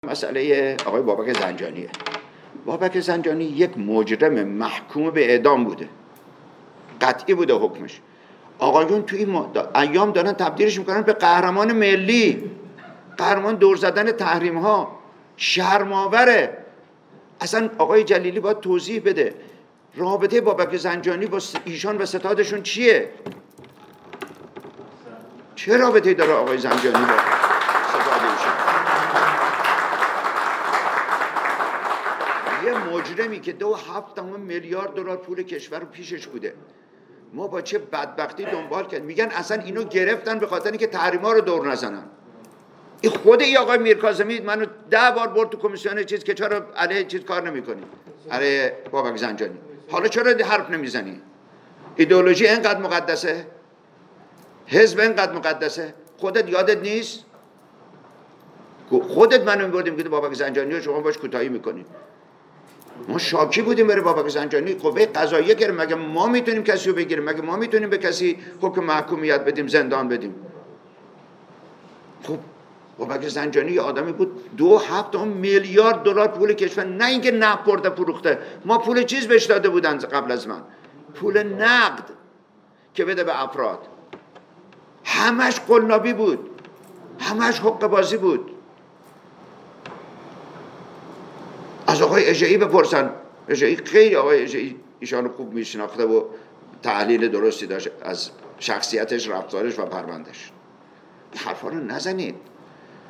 به گزارش خبرگزاری خبرانلاین، وزیر اسبق نفت در نشستی با حضور کارشناسان و متخصصان حوزه‌ی انرژی تاکید کرد: بابک زنجانی یک مجرم محکوم به اعدام با حکم قطعی بوده است، آقایان در این مدت تلاش دارند وی را تبدیل به قهرمان ملی و قهرمان دور زدن تحریم‌ها کنند .شرم اور است .